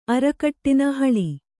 ♪ arakaṭṭina haḷi